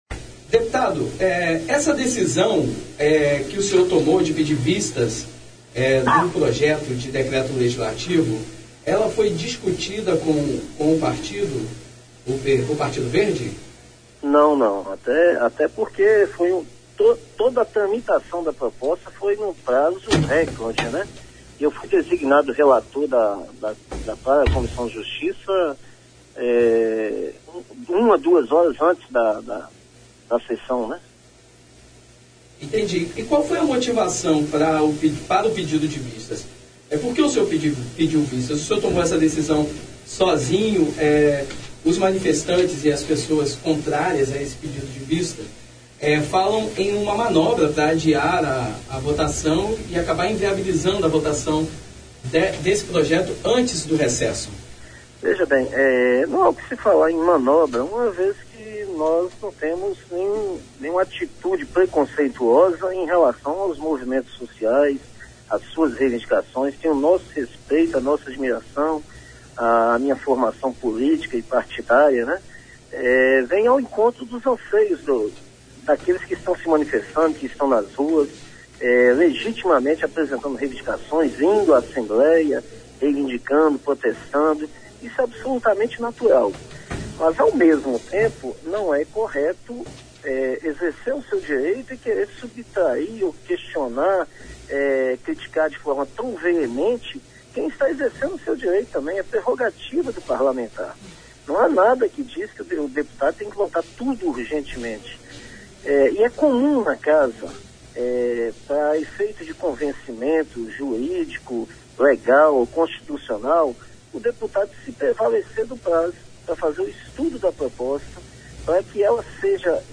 Ocupação na Assembleia: Revista Universitária ouve deputado Gildevan (PV) e manifestante | Ufes FM
O Revista Universitária conversou com o Deputado Estadual Gildevan Fernandes (PV) que explicou as suas razões para o pedido de vistas.
Matéria Ocupação da Ales Download : Matéria Ocupação da Ales